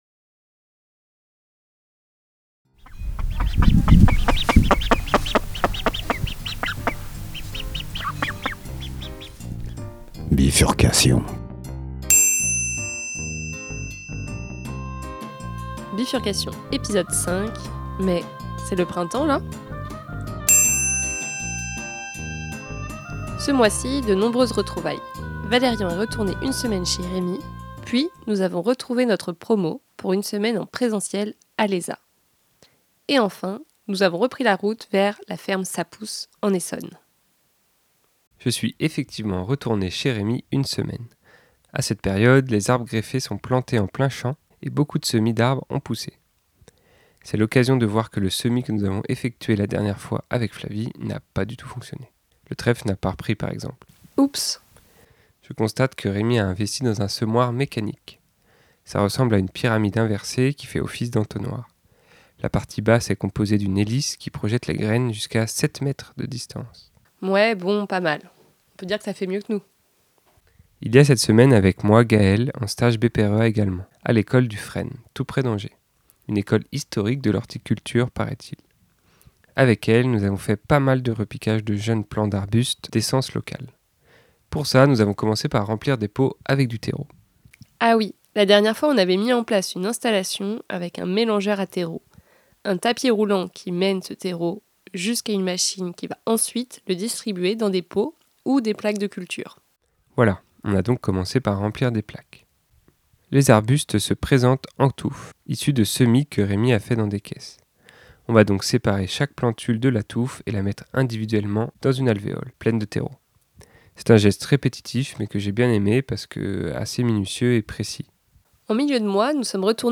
stars du jingle : Momo & Lumpa, les canards coureurs indiens